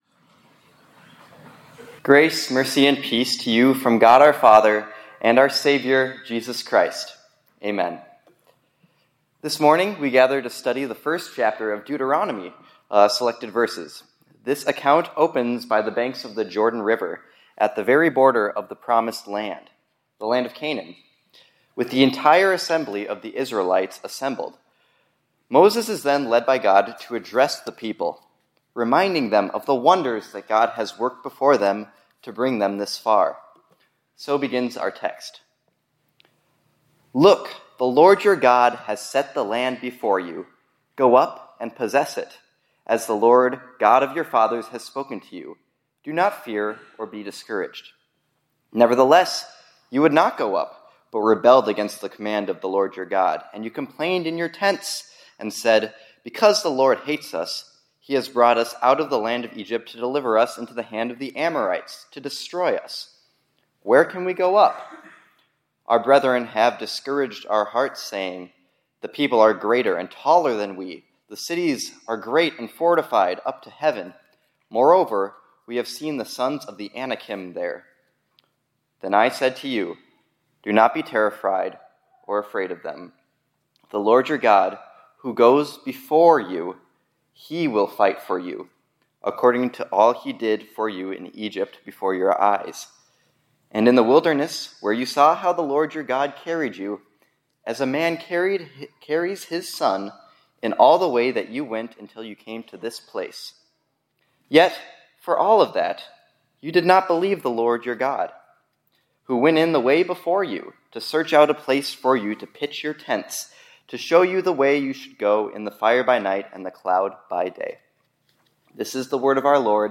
2024-11-18 ILC Chapel — The Lord Goes Before Us